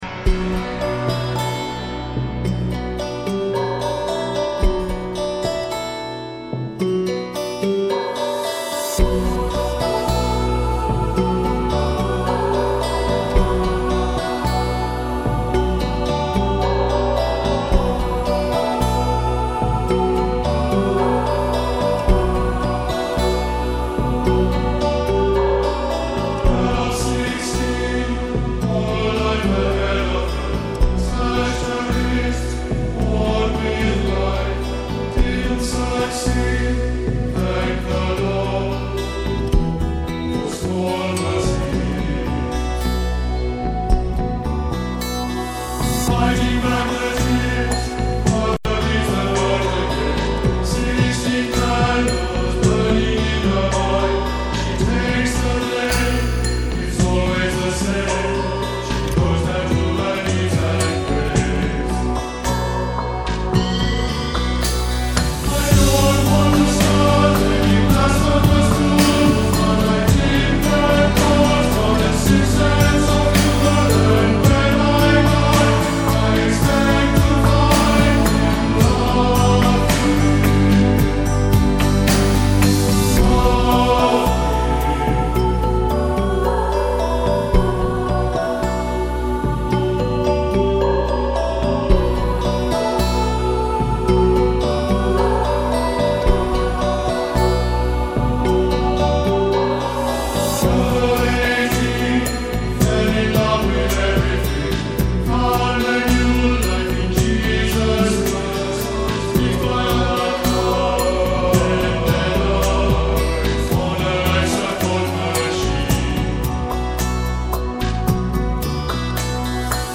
还加入摇滚乐的乐器编制